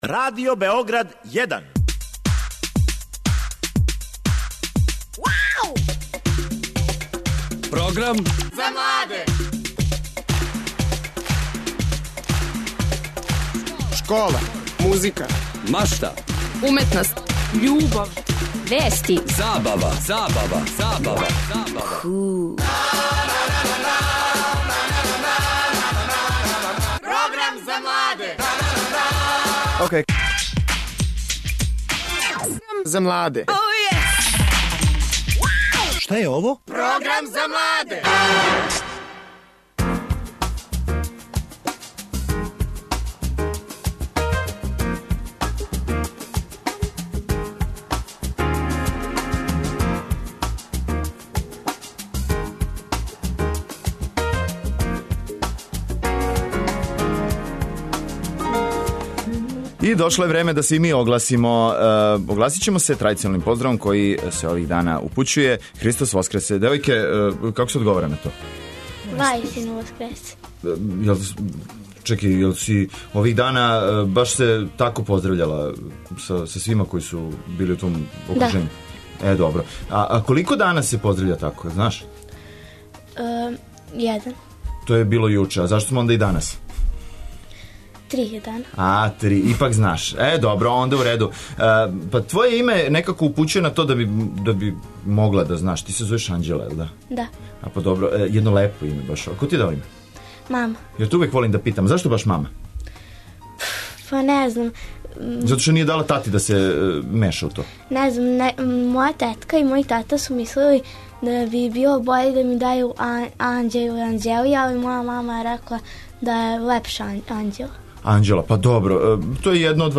Да би смо то установили, помоћи ће нам наши мали другари из Културно уметничког друштва „Извор".